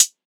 SOUTHSIDE_hihat_clubber.wav